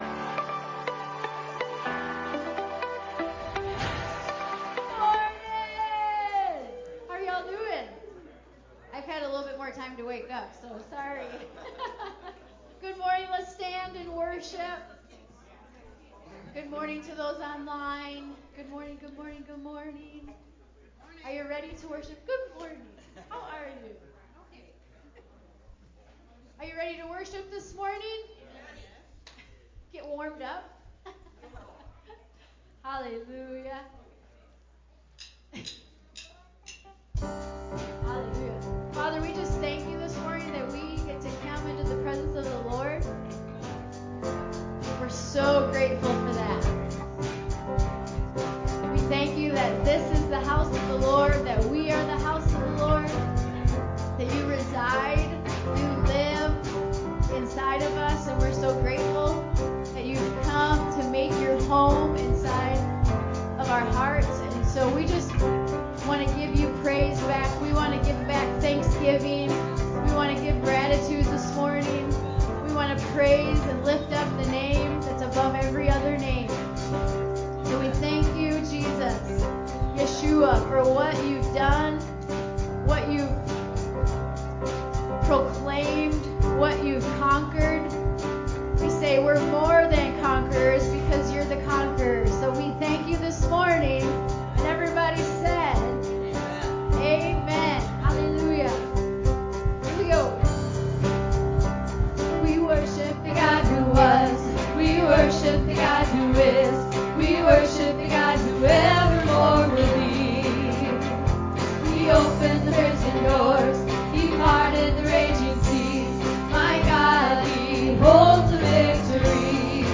wlfc_fearless_part5_allService-CD.mp3